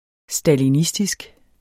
Udtale [ sdaliˈnisdisg ] Betydninger vedr. eller tilhørende stalinismen